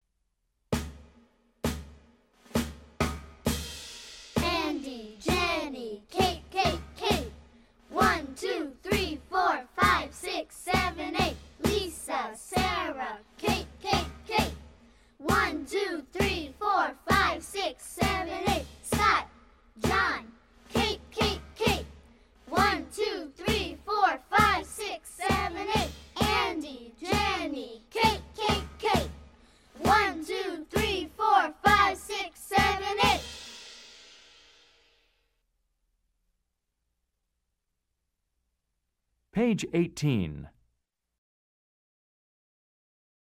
牛津少儿英语 let's chant 32 - The Name Chant (group) - 41 s. 听力文件下载—在线英语听力室